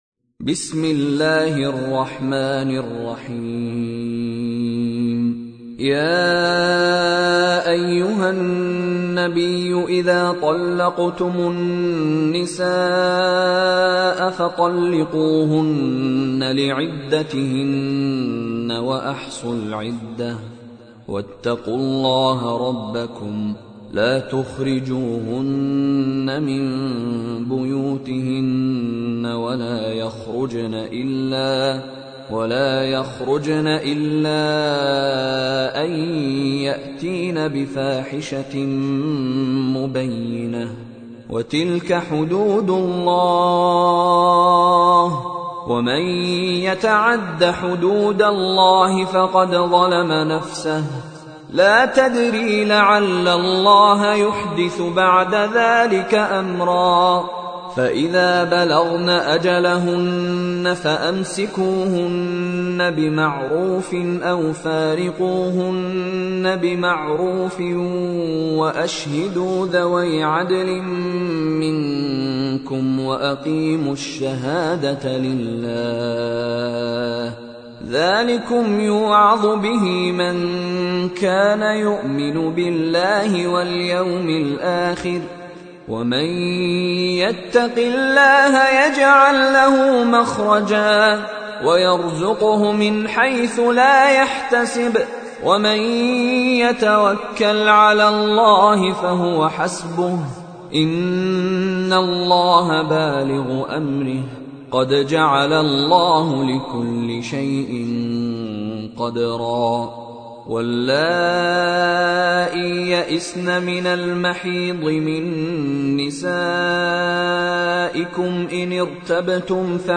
پخش آنلاین و دانلود قرائت آیات هفته اول مرداد ماه ، قرآن پایه ششم ، با قرائت زیبا و روحانی استاد مشاری رشید العفاسی